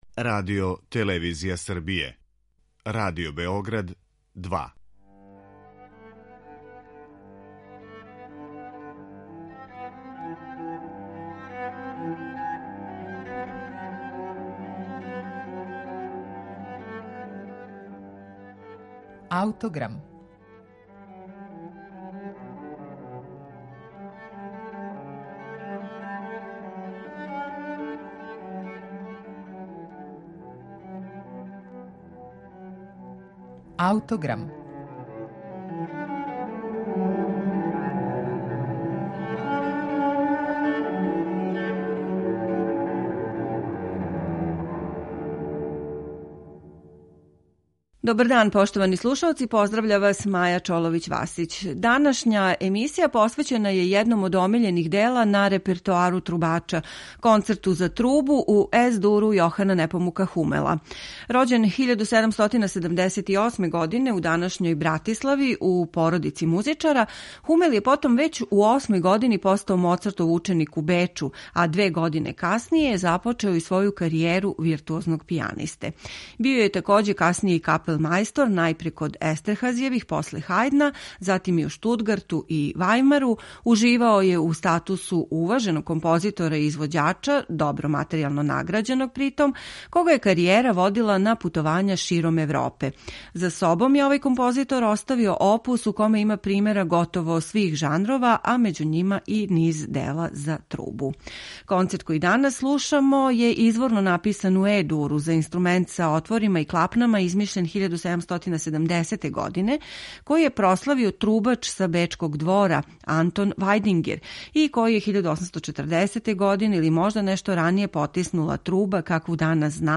Реч је о делу писаном за нови тип инструмента из осме деценије XVIII века које је премијерно представљено публици тек 1803. године. Концерт уобичајене троставачне структуре и препознатљивог класичарског израза, слушаћете у интерпретацији славног Мориса Андреа.